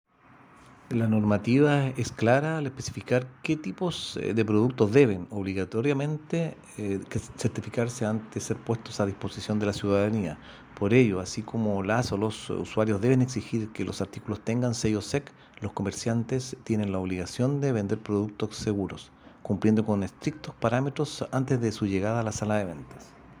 Audio: Humberto Rovegno, Director Regional SEC Los Ríos